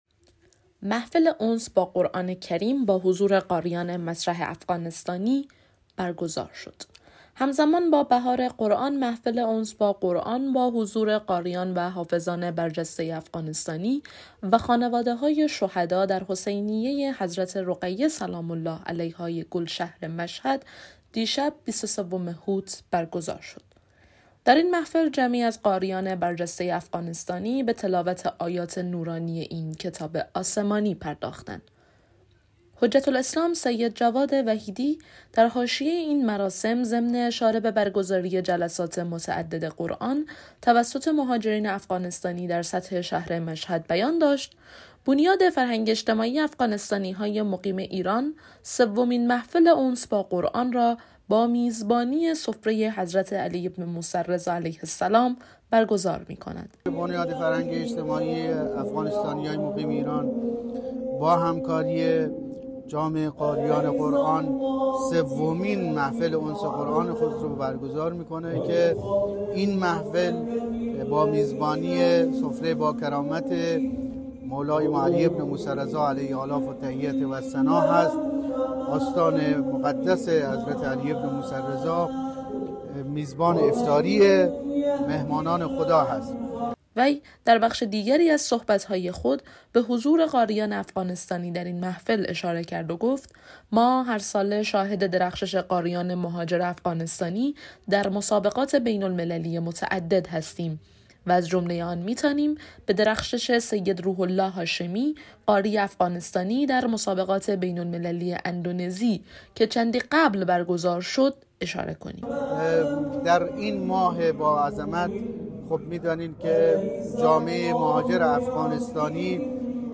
به مناسبت ایام ماه مبارک رمضان محفل انس با قرآن کریم با حضور قاریان و حافظان برجسته و خانواده های شهدای افغانستانی در گلشهر مشهد برگزار شد.